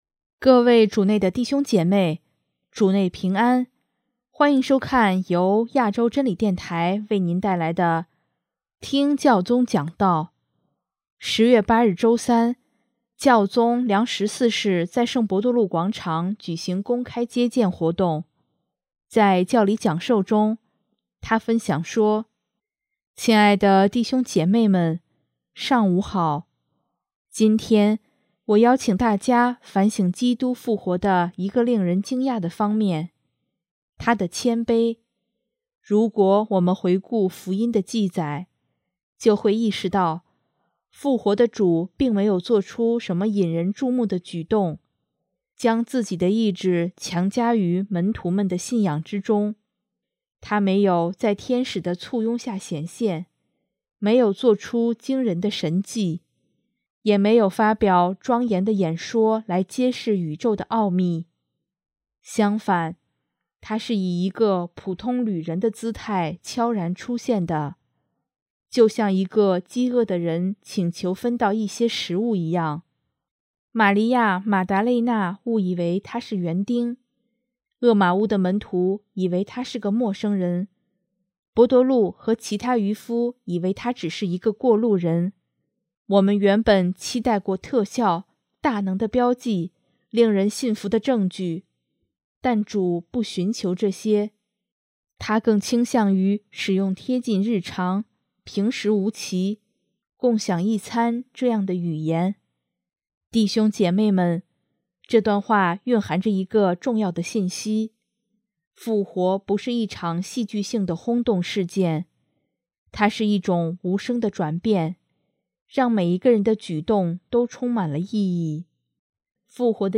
10月8日周三，教宗良十四世在圣伯多禄广场举行公开接见活动。